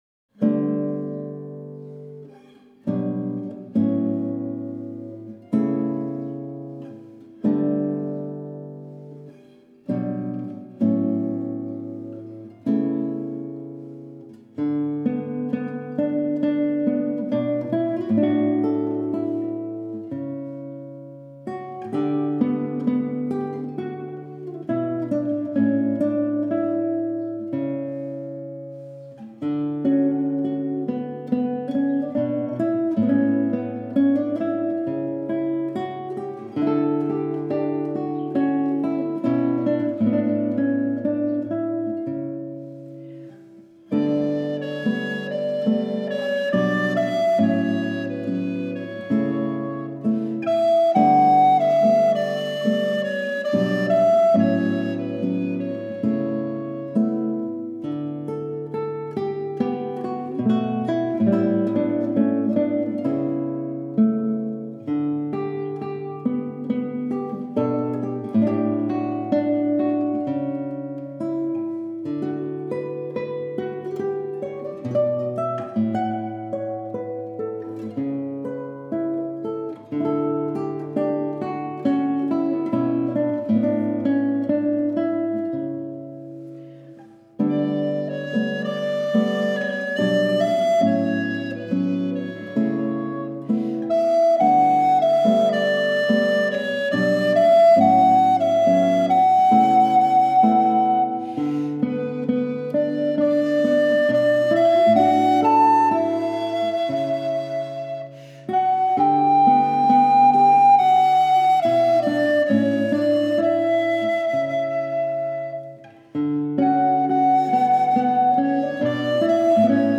recorder
guitar
Recorded at Baltic Recording studio